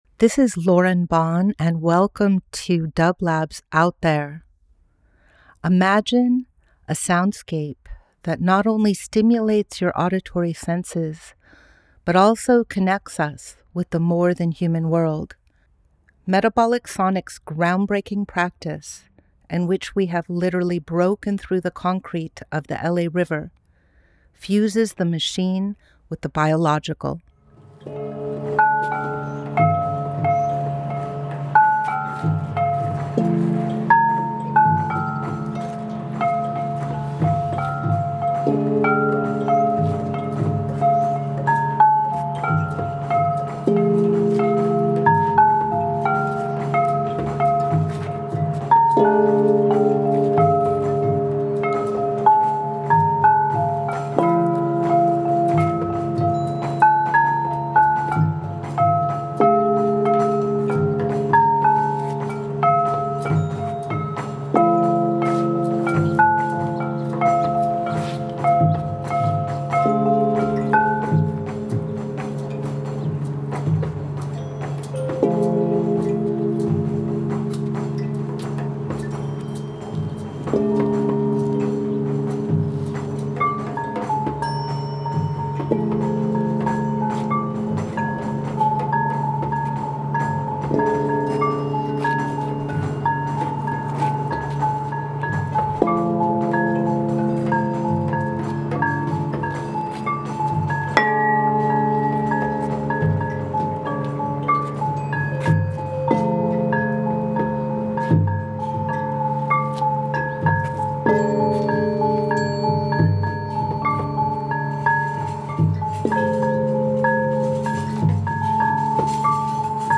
percussion and metallophones
Ambient Experimental Field Recording Fourth World